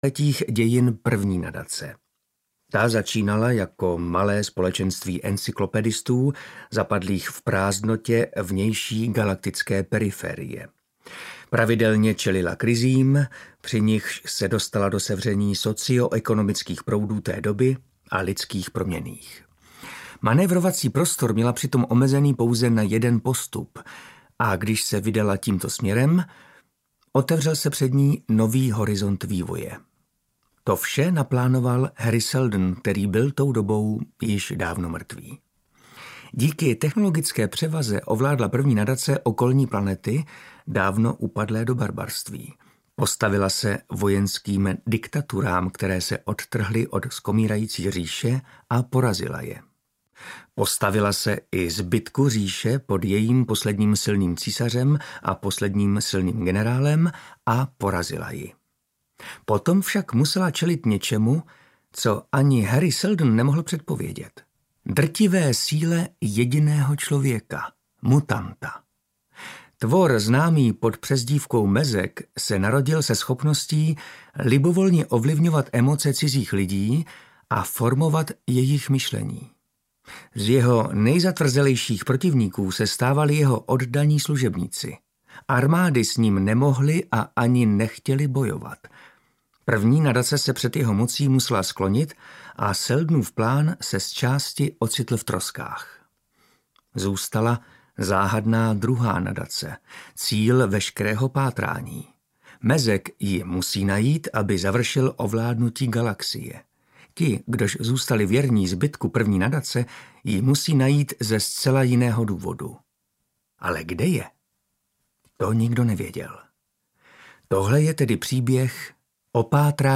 Druhá Nadace audiokniha
Ukázka z knihy
• InterpretMartin Myšička